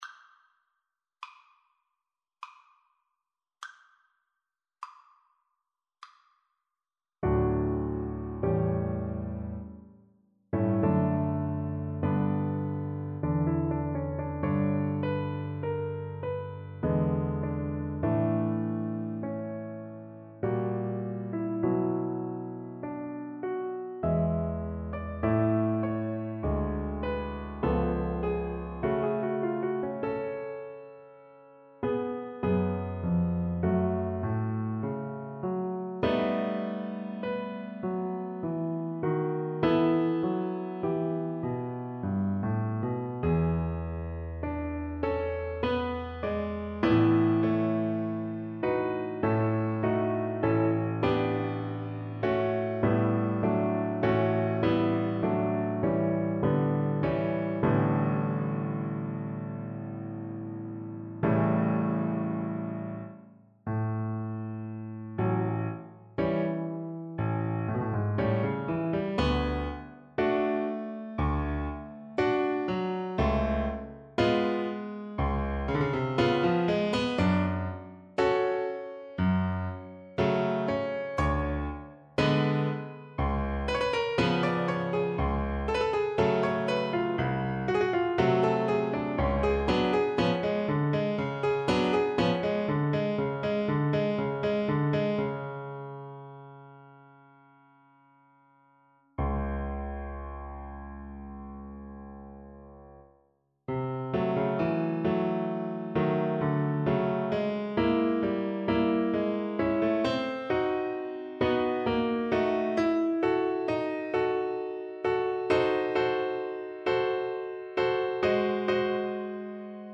3/4 (View more 3/4 Music)
Cantabile (=50) Adagio molto
Classical (View more Classical Cello Music)